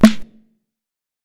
TC3Snare20.wav